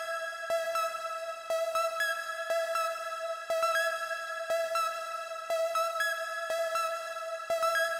被困的合成器
Tag: 120 bpm Trap Loops Synth Loops 1.35 MB wav Key : C